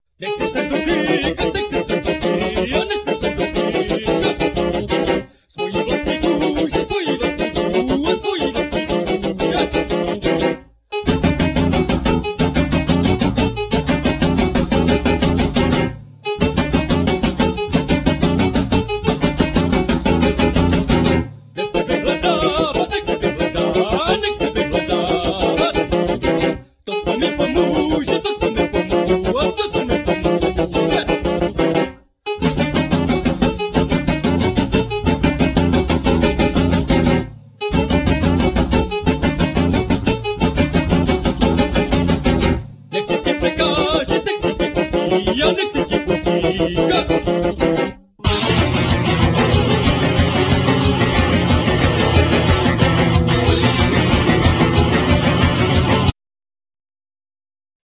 Guitar,Keyboards,Vocal
Tennor saxophne,Vocal
Bass,Vocal
Brushes,Stetce